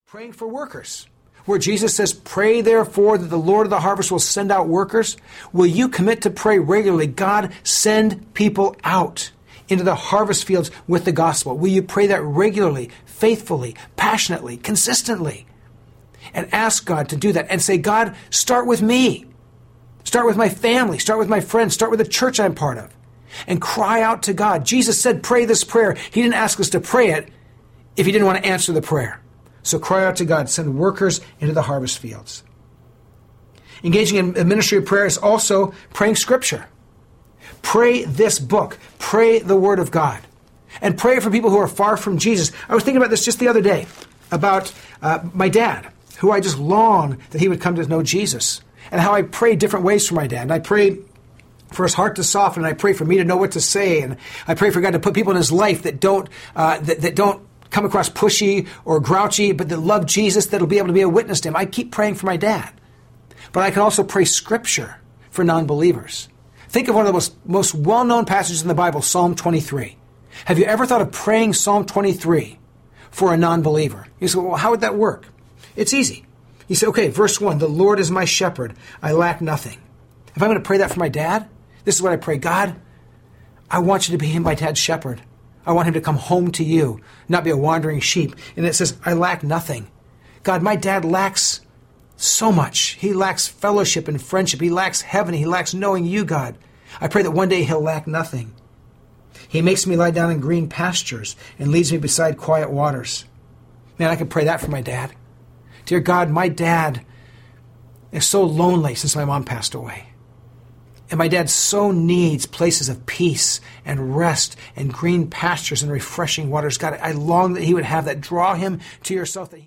6.08 Hrs. – Unabridged